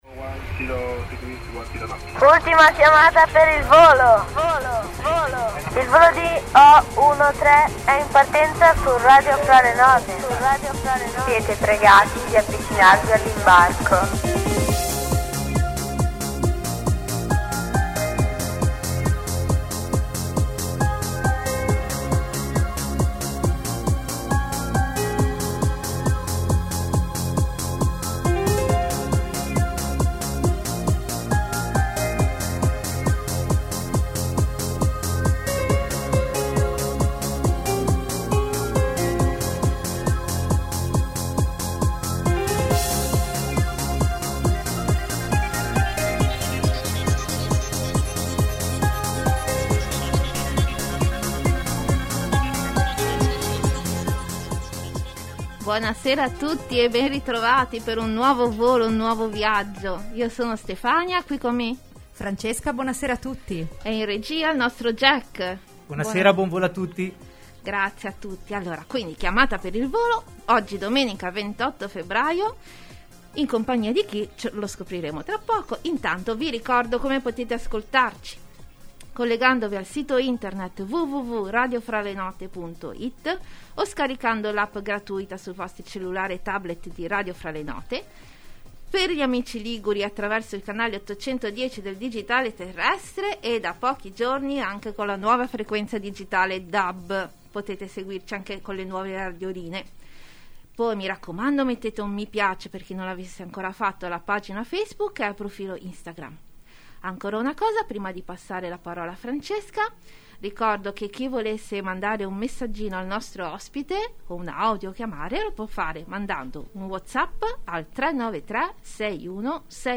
In onda la domenica sera alle 21:00 in diretta dalla Sede centrale di Radio Fra le note in Via Minoretti di Genova